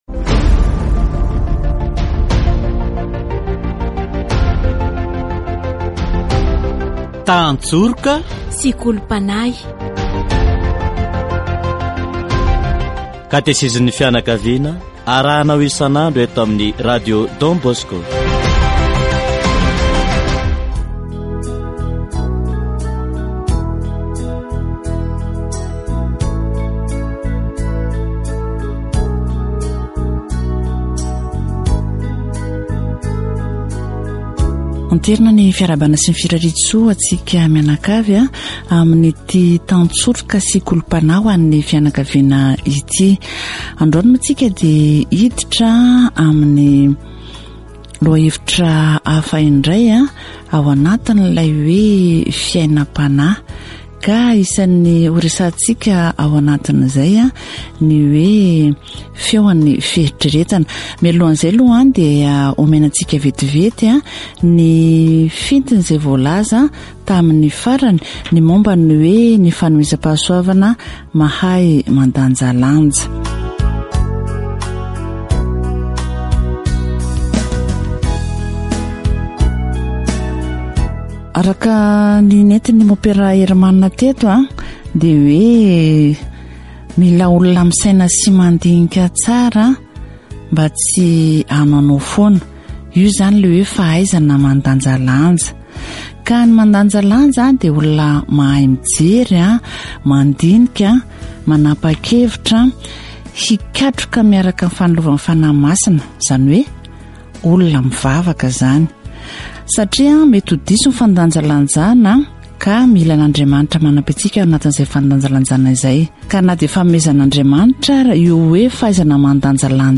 Katesizy momba ny feon'ny fieritreretana